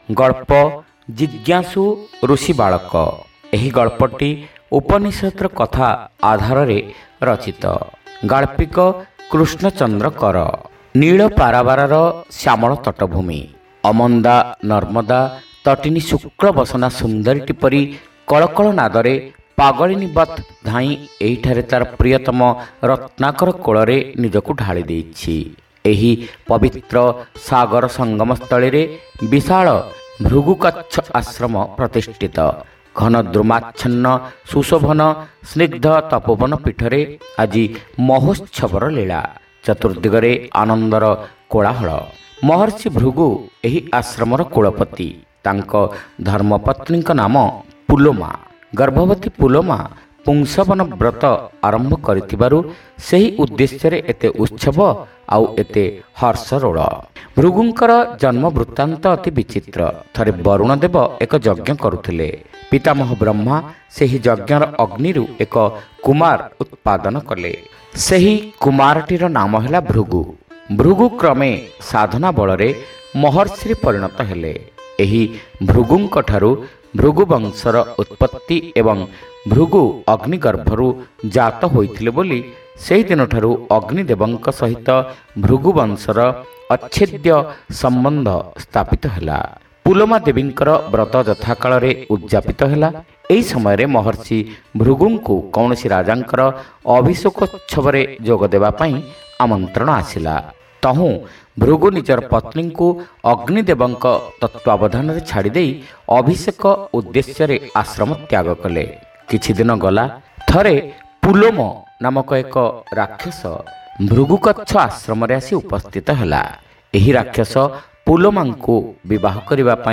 Audio Story : Jingyasu Rushi Balaka